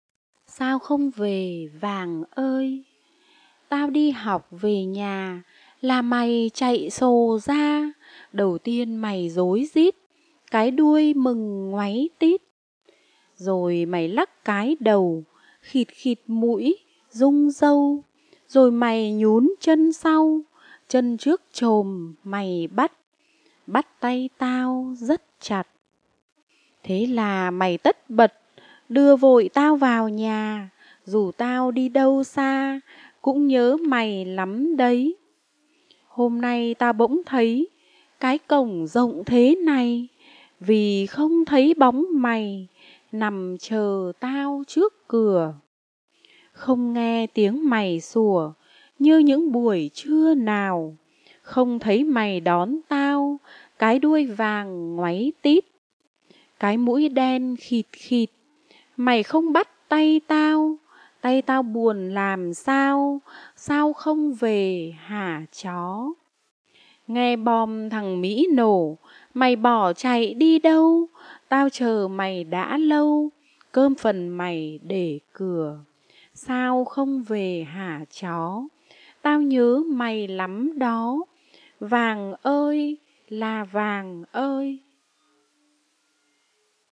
Sách nói | Bài thơ "Sao không về vàng ơi"